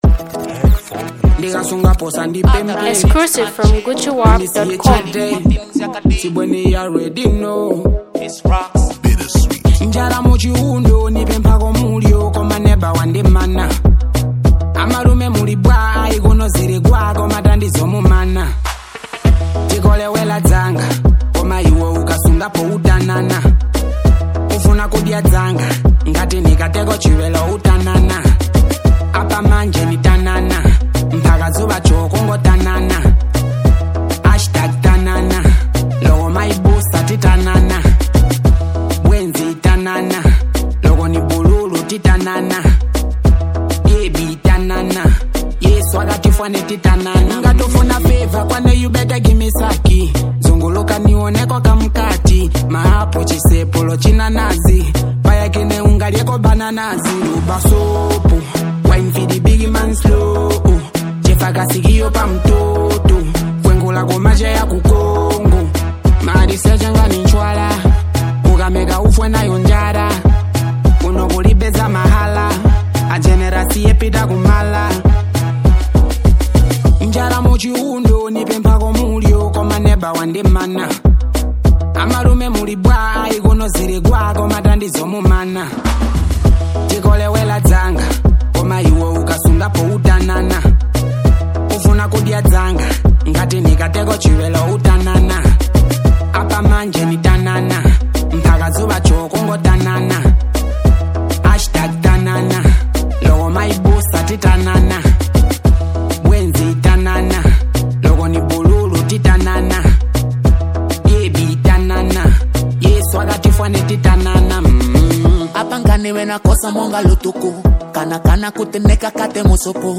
a ghetto vibe banger